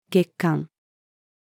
月刊-monthly-publication-female.mp3